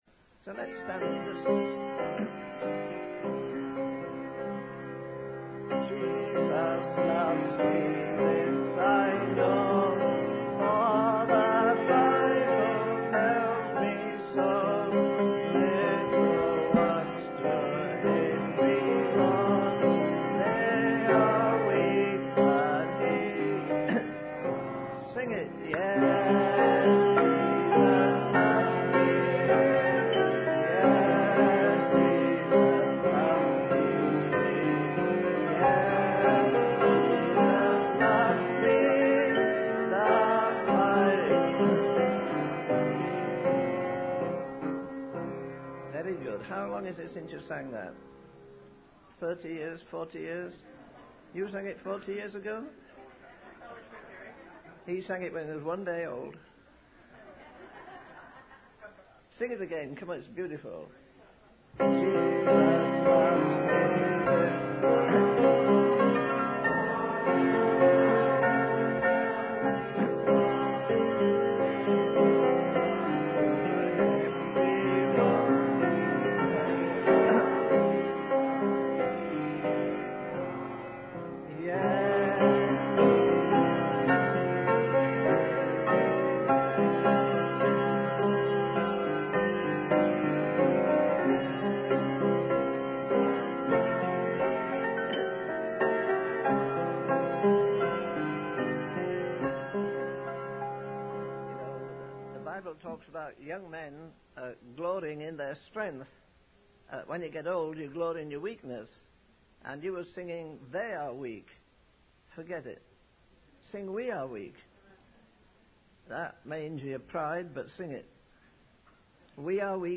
In this sermon, the speaker discusses the story of Hannah from the Bible and the elements of true intercession.